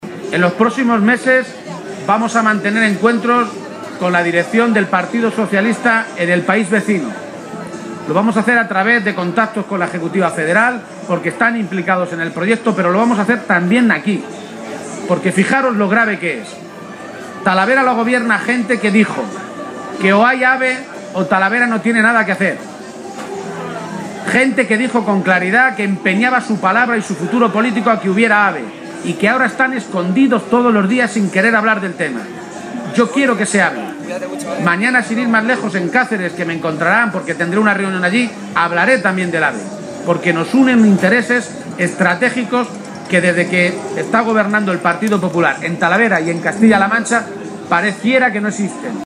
García-Page se pronunciaba de esta manera este mediodía, en una comparecencia ante los medios de comunicación durante su visita a la Feria de Talavera.